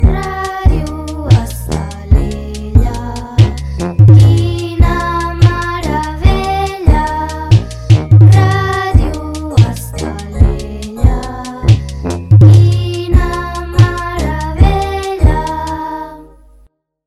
Indicatiu cantat de la ràdio